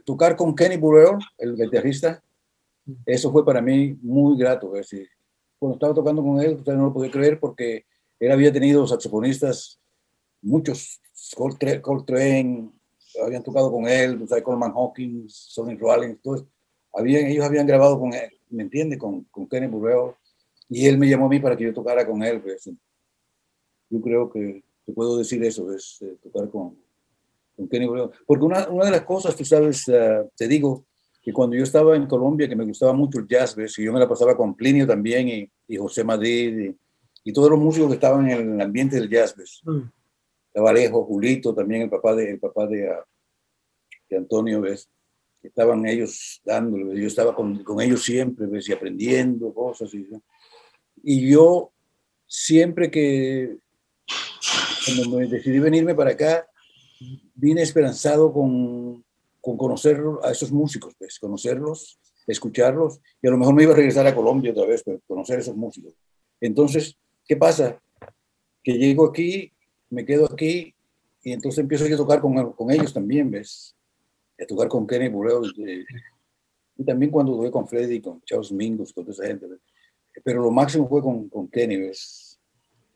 Entrevista personal vía Skype, 22 de marzo de 2021